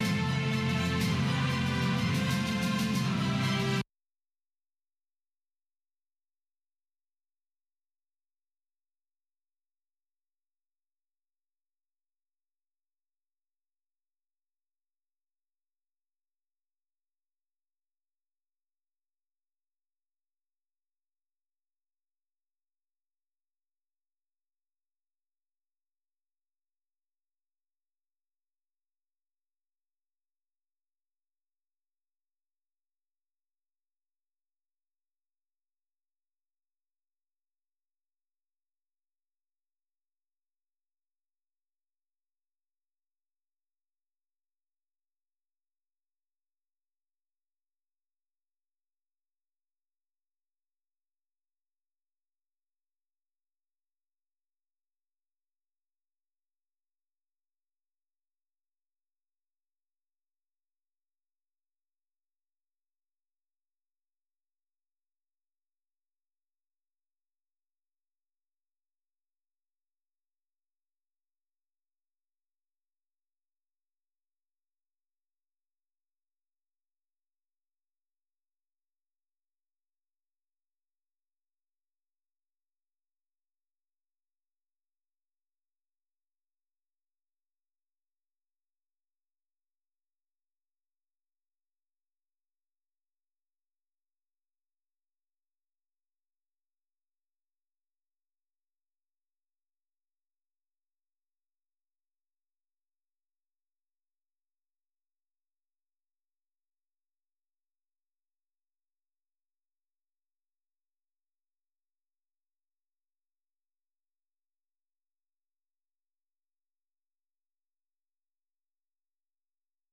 အပြည်ပြည်ဆိုင်ရာ စံတော်ချိန် ၂၃၃၀ ၊ မြန်မာစံတော်ချိန် နံနက် ၆ နာရီကနေ ၇ နာရီထိ (၁) နာရီကြာ ထုတ်လွှင့်နေတဲ့ ဒီ ရေဒီယိုအစီအစဉ်မှာ မြန်မာ၊ ဒေသတွင်းနဲ့ နိုင်ငံတကာ သတင်းနဲ့ သတင်းဆောင်းပါးတွေ သီတင်းပတ်စဉ်ကဏ္ဍတွေကို နားဆင်နိုင်ပါတယ်။